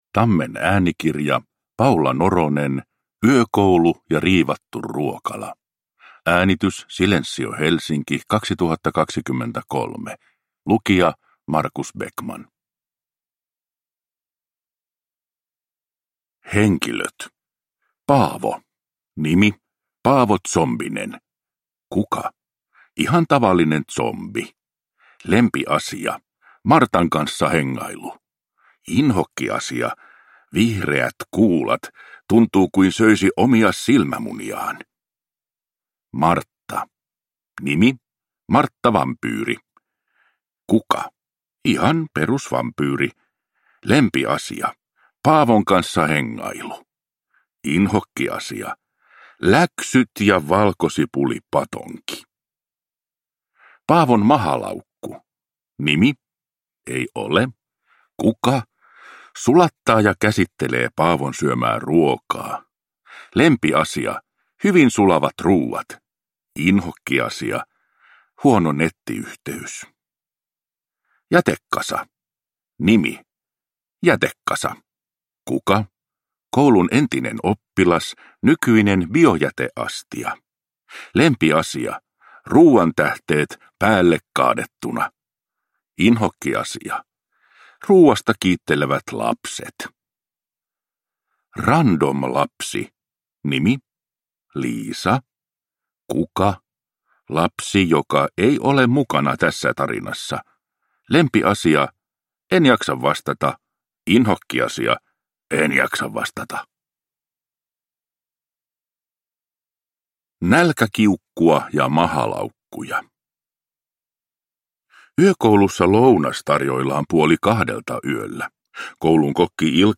Yökoulu ja riivattu ruokala – Ljudbok